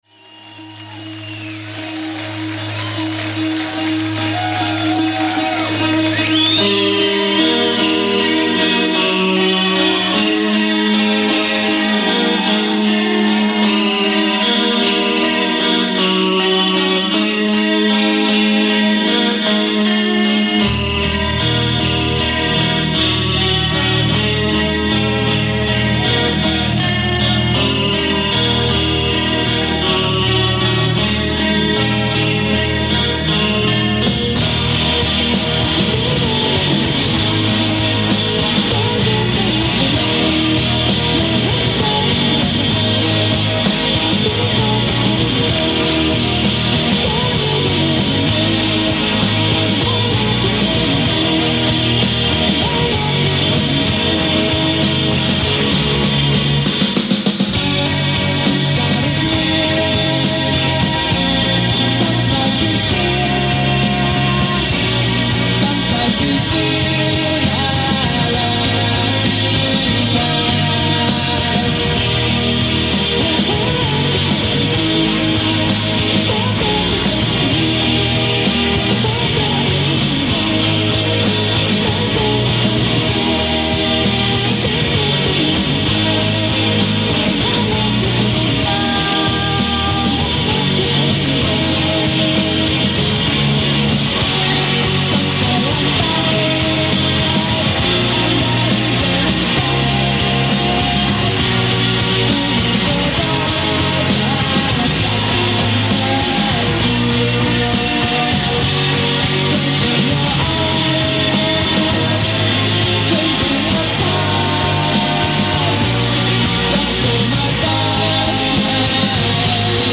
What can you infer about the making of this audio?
so the sound quality is excellent… live at the Metro in Chicago